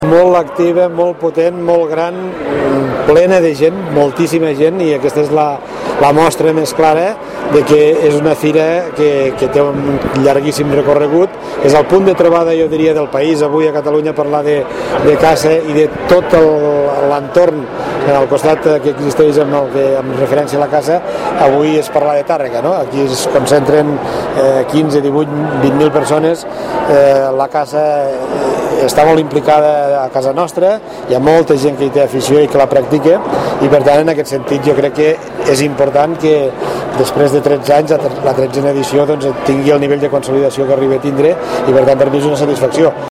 En la seva intervenció, Reñé ha remarcat la gran activitat firal que existeix a les comarques lleidatanes, amb més de 160 fires i mostres de tot tipus, des de multisectorials fins a especialitzades, com és el cas de la Fira del Caçador de Tàrrega, que compta amb el suport de la corporació lleidatana.